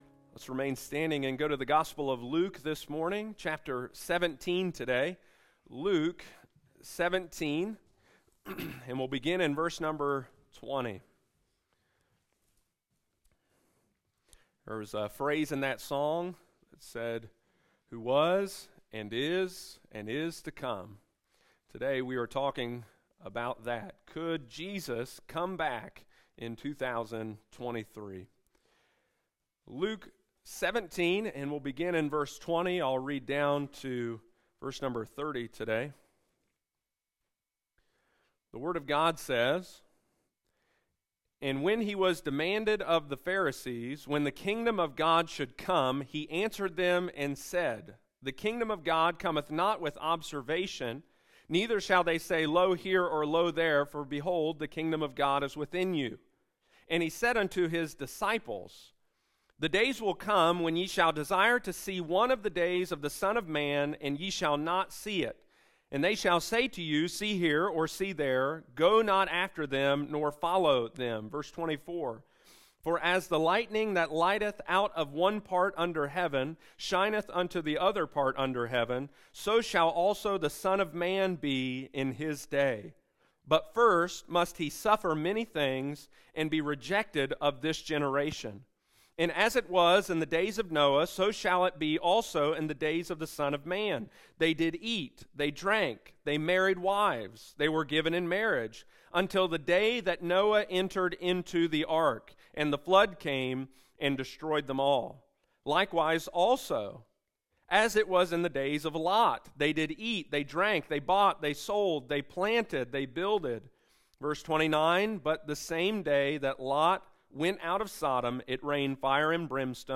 This week we are starting the new year focusing on the second coming of Christ! Sunday morning, January 1, 2023.